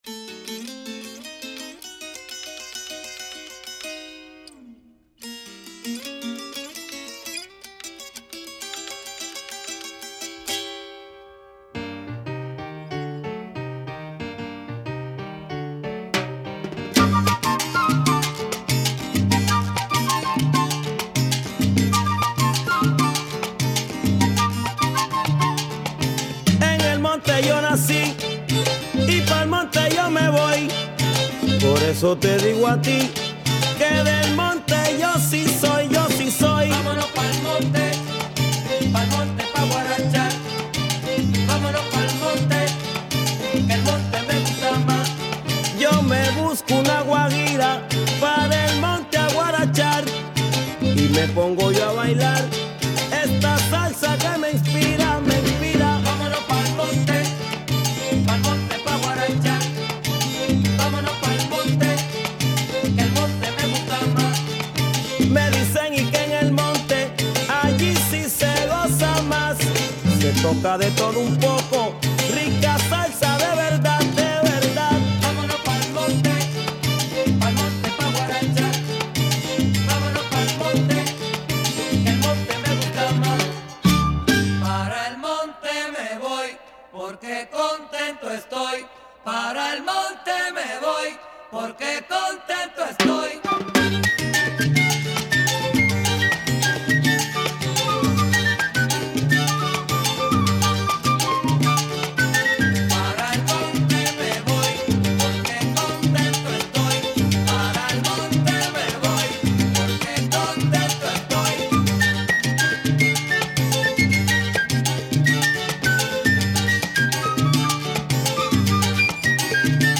Two great latin tunes here